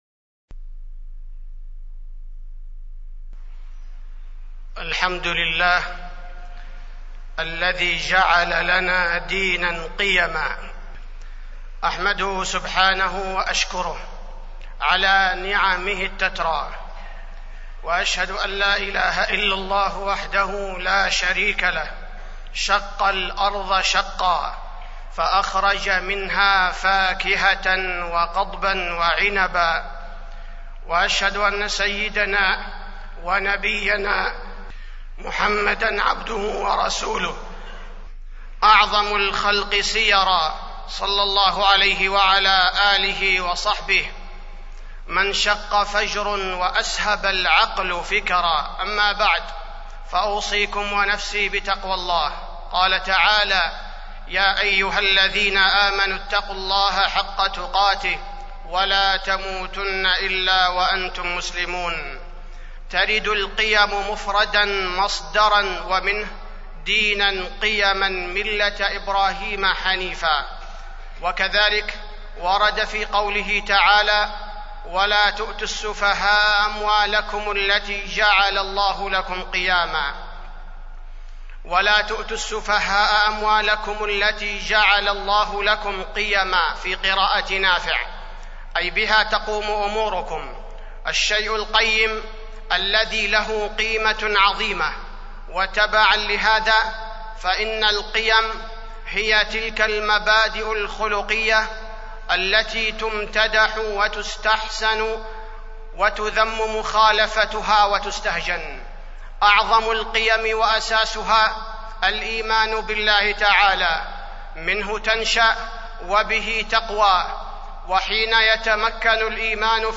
تاريخ النشر ٧ ربيع الثاني ١٤٢٧ هـ المكان: المسجد النبوي الشيخ: فضيلة الشيخ عبدالباري الثبيتي فضيلة الشيخ عبدالباري الثبيتي القيم الإسلامية